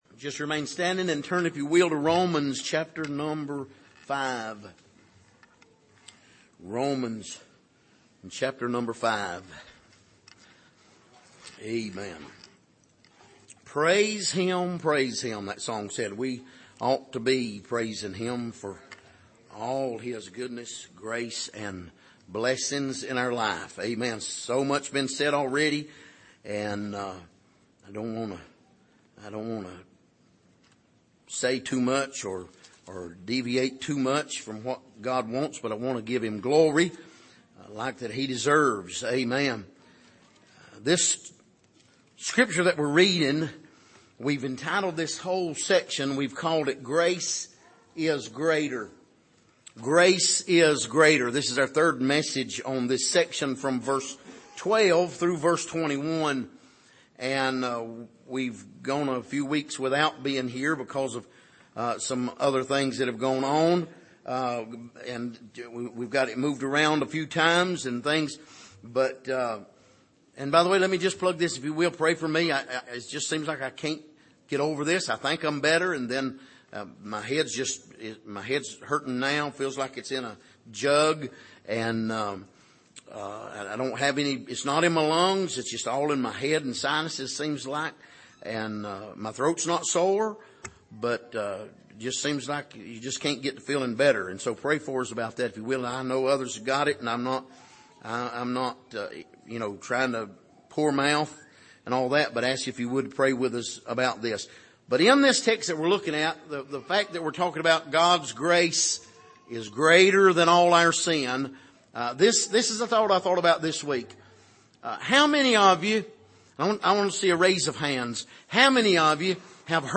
Passage: Romans 5:18-21 Service: Sunday Morning